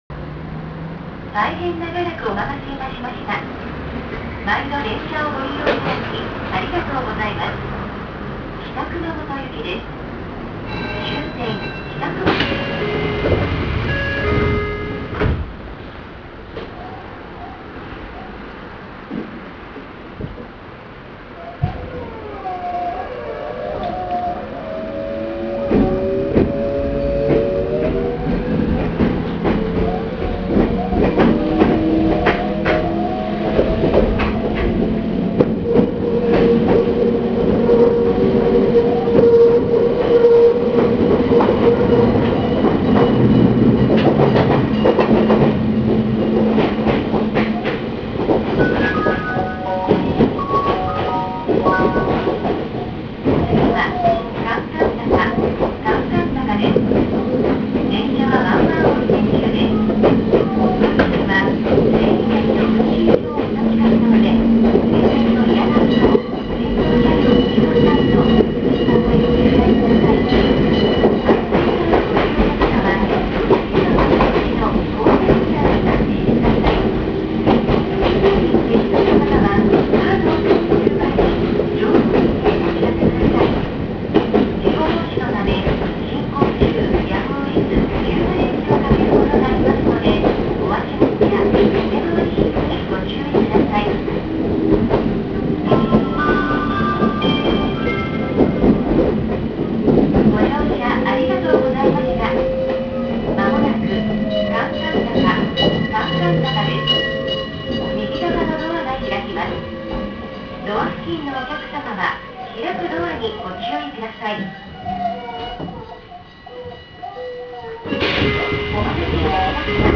・01形走行音
【菊池線】上熊本〜韓々坂（2分1秒）…登場時
車内の雰囲気やドアチャイムは銀座線時代と全く変わりませんが、熊本電鉄の自動放送が設置され、VVVFインバータ制御も別の物に変更されており、東芝IGBTとなっています。